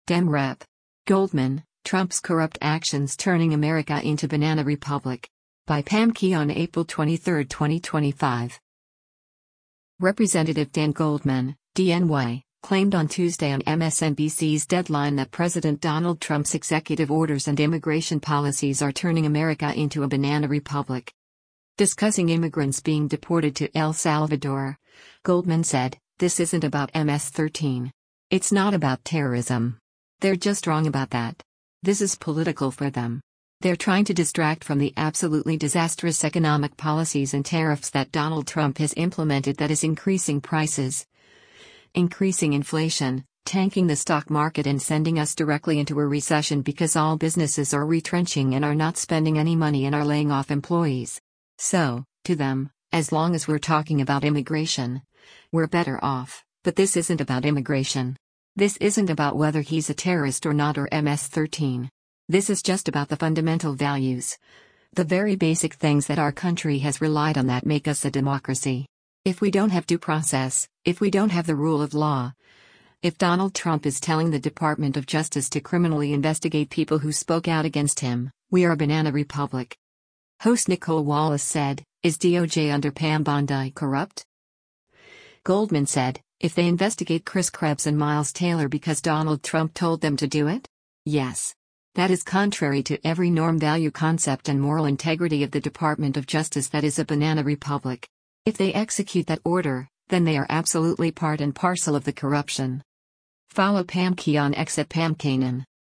Representative Dan Goldman (D-NY) claimed on Tuesday on MSNBC’s “Deadline” that President Donald Trump’s executive orders and immigration policies are turning America into a “banana republic.”